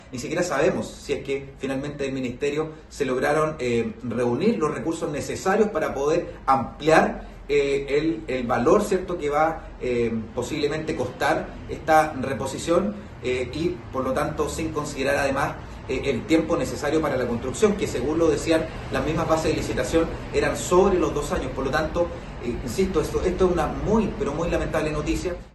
En tanto, el concejal de Concepción, Boris Negrete, lamentó que la iniciativa no avance, mientras tanto, el terreno donde se prometió que se emplazaría el proyecto sigue abandonado.